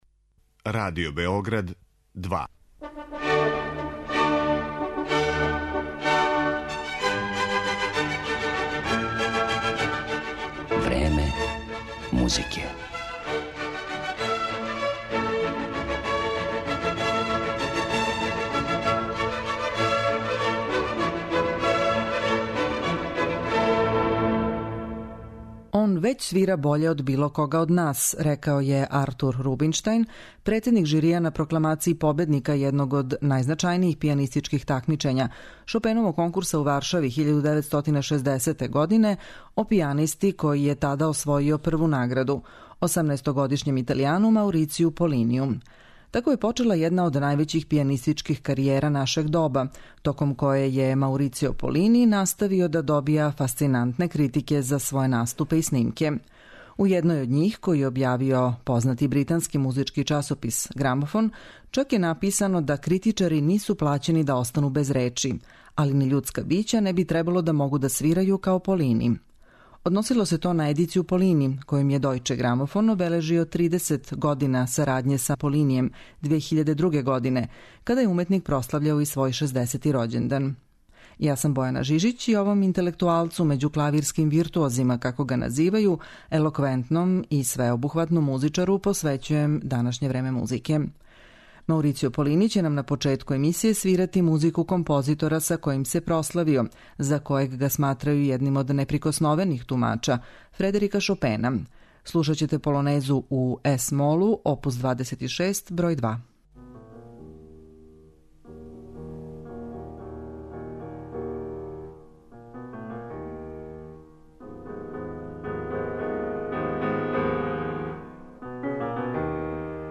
Једном од највећих пијаниста нашег доба, Маурицију Полинију, који има репутацију не само врхунског виртуоза него и иинтелектуалца међу музичарима, елоквентног и свеобухавотног уметника, посвећена је данашња емисија 'Време музике'.
Овај славни италијански солиста ће изводити композиције Фредерика Шопена, Јоханеса Брамса, Игора Стравинског, Лудвига ван Бетовена и Роберта Шумана.